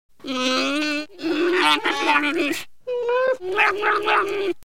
It is not an easy language for humans and other humanoids to learn, as most of the sounds emanate as growls and howls from the back of the throat.
Wookiee 2
SHYRIIWOOK LANGUAGE SAMPLE SOUNDS